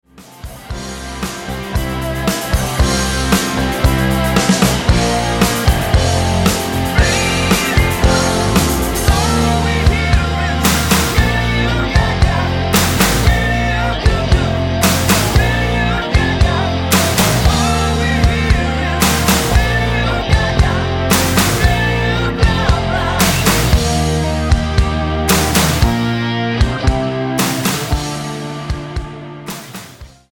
Tonart:F mit Chor